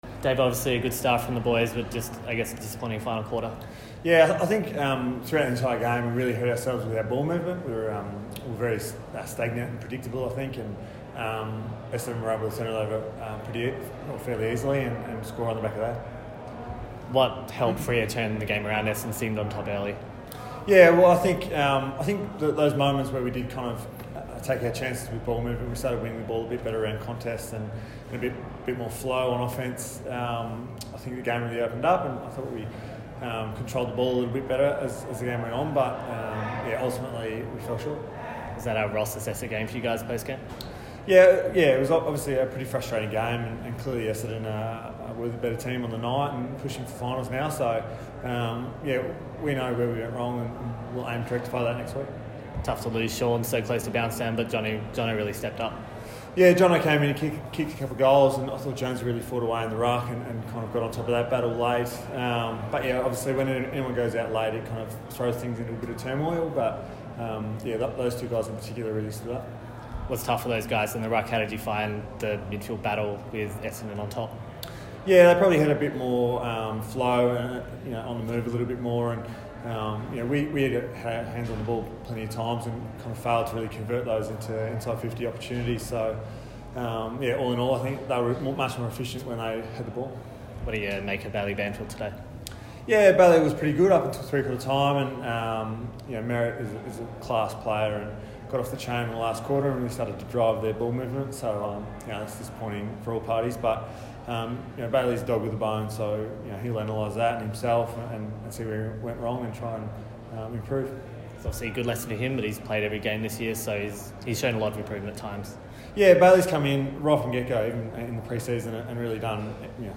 Dave Mundy chats to Docker TV after Rd 18 against the Bombers